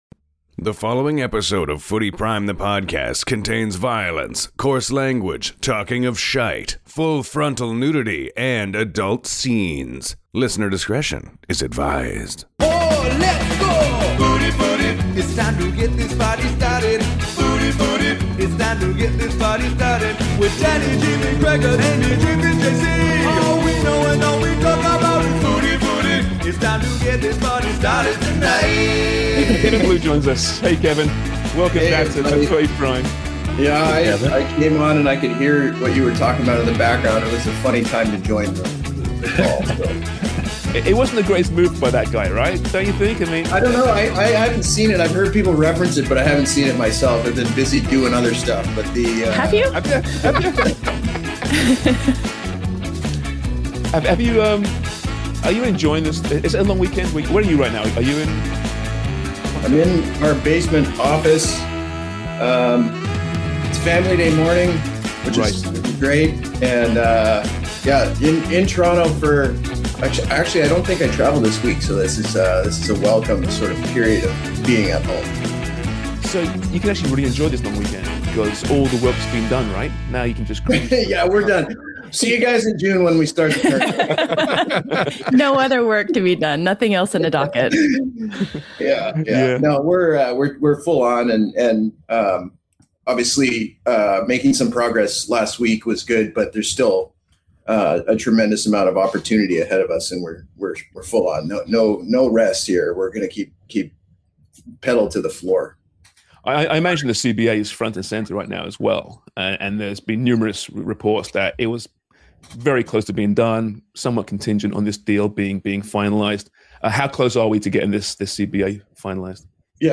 CEO & General Secretary’s Latest INTVU, Edited for Time and to Fit the Screen